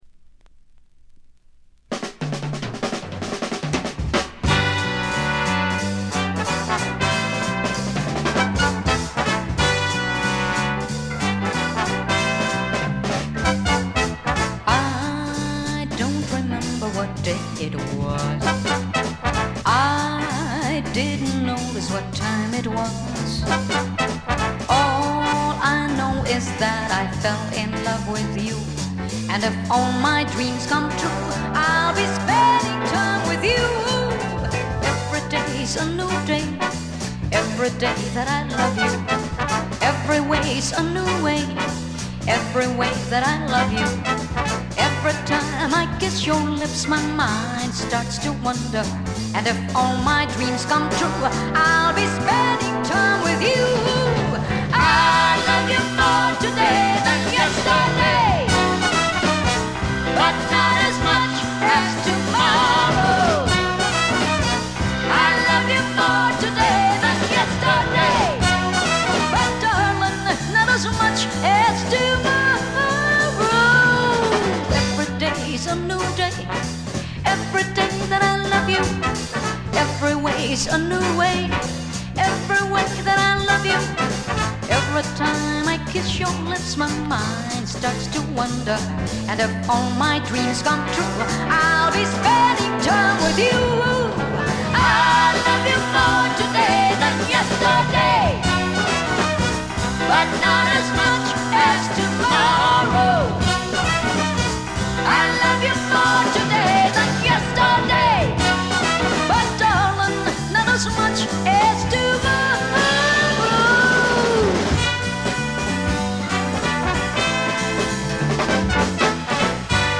フィリピン出身の女性シンガー
ライブ録音らしくバンドと一体感のあるグルーヴが素晴らしいです。
使用感という意味では新品同様極美品ですが、自主盤らしいプレス起因と思われる軽微なチリプチが少し聴かれます。
試聴曲は現品からの取り込み音源です。